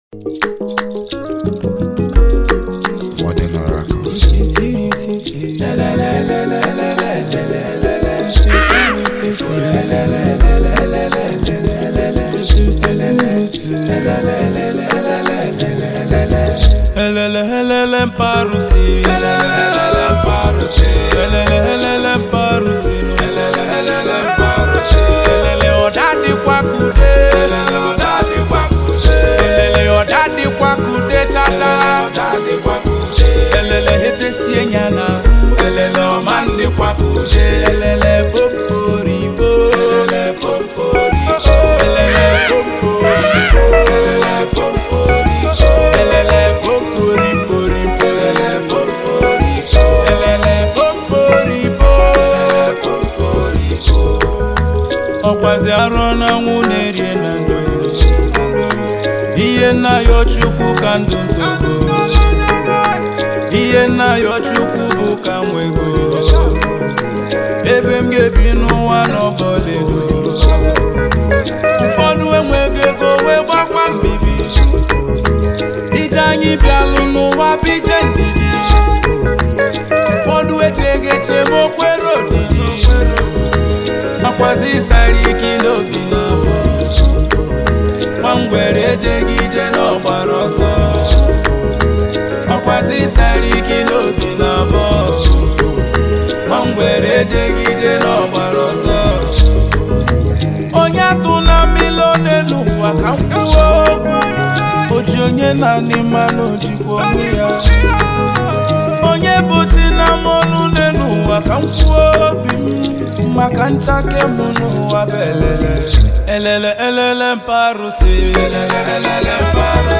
October 15, 2024 admin Highlife Music, Music 0
Popular Nigerian Highlife Music Duo
highlife single